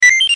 stalker pda sound Meme Sound Effect
Category: Games Soundboard
stalker pda sound.mp3